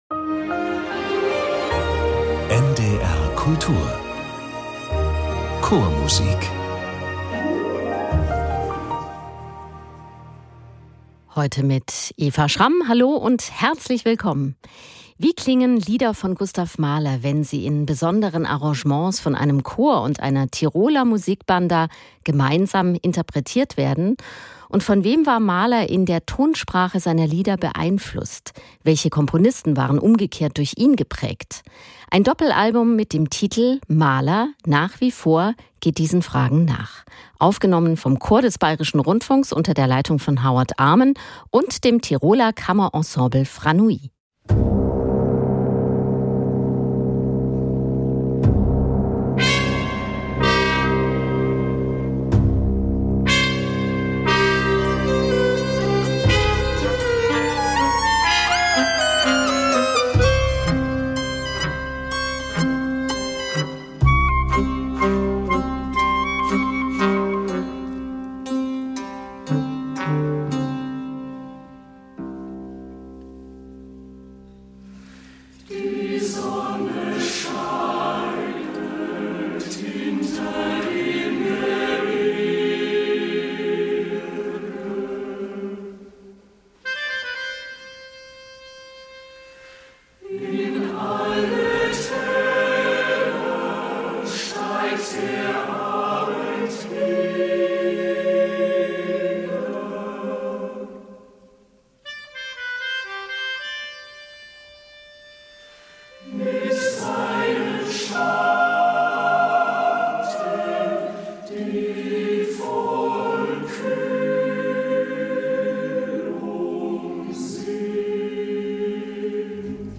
Mit der Südtiroler Musicbanda Franui interpretiert der Chor des Bayerischen Rundfunks Lieder von Mahler, Loewe und Grosz.